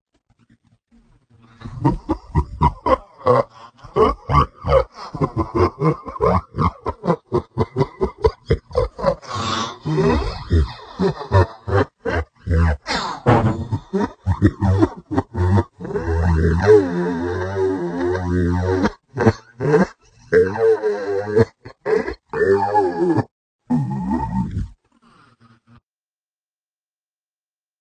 Risa
Meme Sound Effect